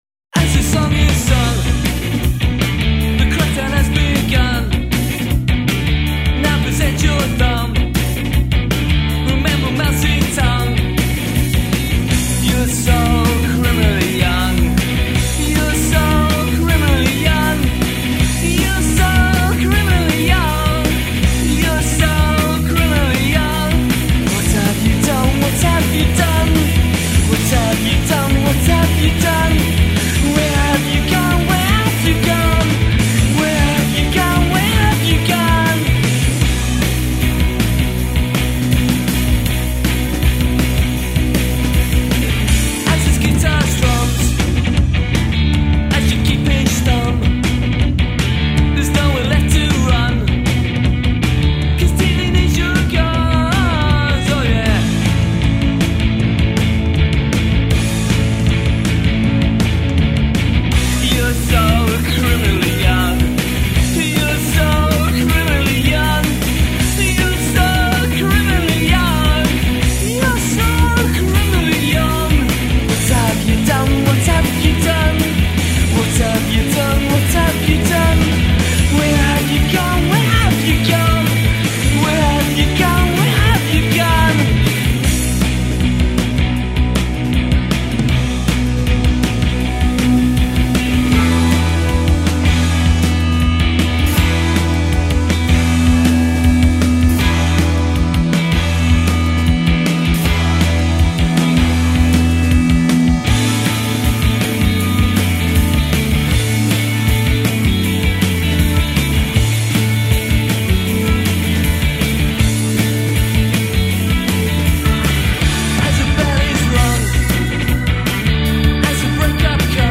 vampata di incoscienza punk, irruente e leggerissima